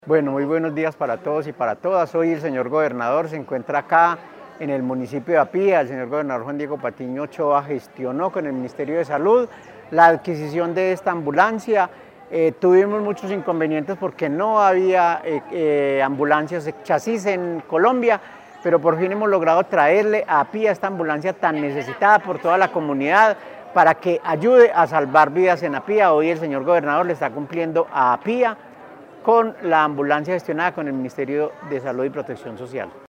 Por su parte, el secretario de Salud de Risaralda, Édgar Hernando Navarro Zuluaga reiteró las bondades de esta entrega: “Hemos llegado a traerle a Apía esta ambulancia tan necesitada por toda la comunidad para que ayude a salvar vidas” puntualizó.